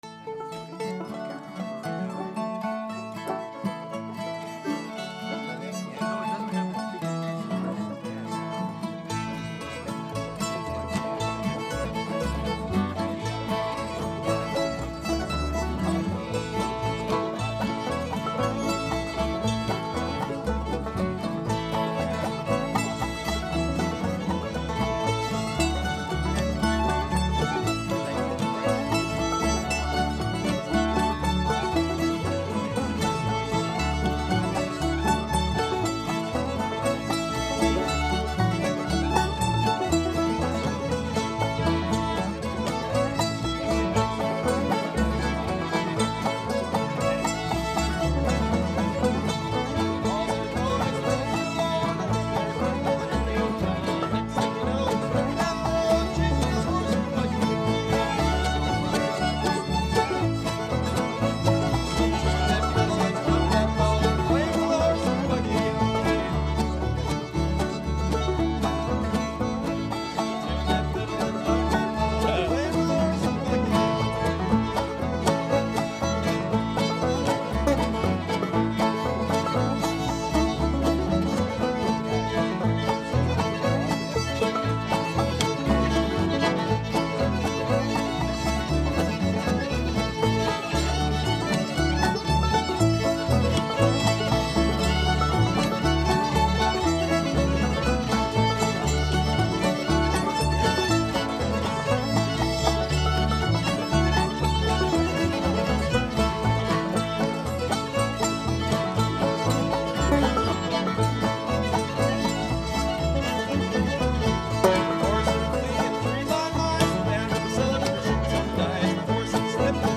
recorded by a bunch of musical friends